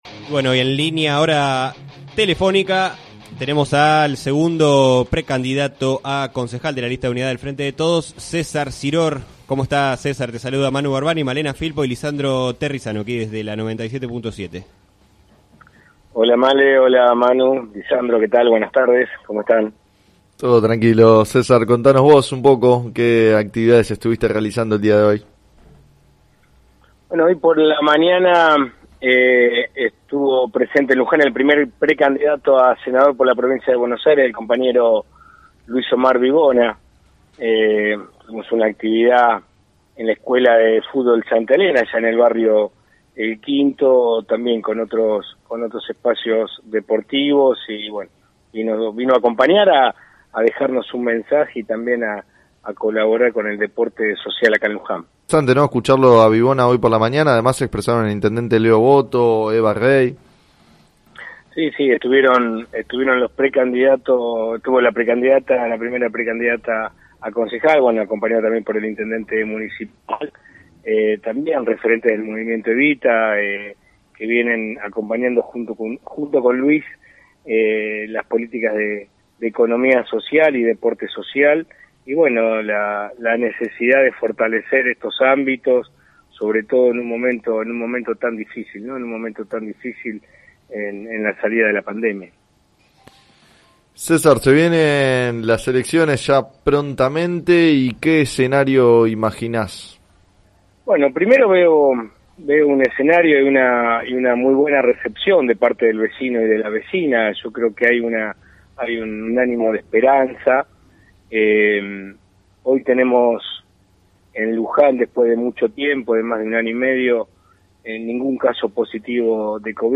En declaraciones al programa “Sobre las cartas la mesa” de FM Líder 97.7, Siror consideró que resultó posible llegar a esta situación gracias a las políticas de los gobiernos nacional, provincial y municipal y destacó el esfuerzo de los trabajadores de la salud.